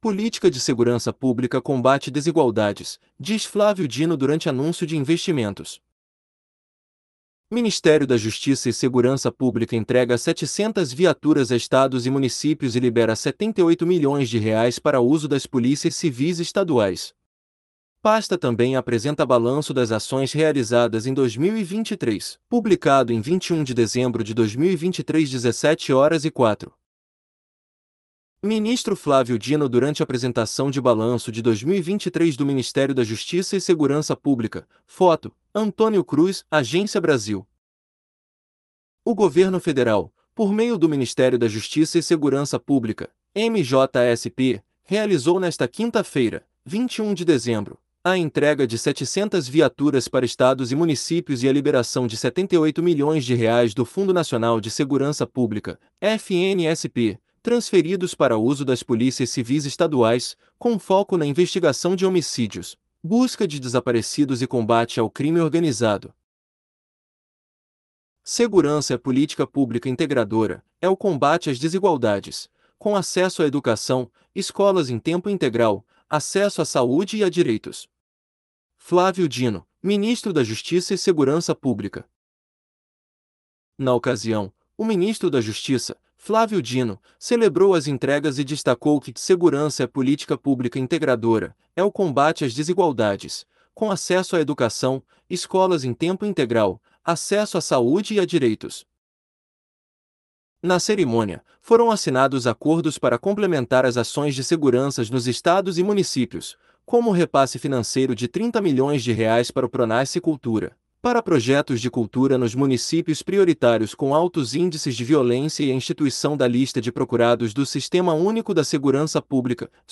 Política de Segurança Pública combate desigualdades, diz Flávio Dino durante anúncio de investimentos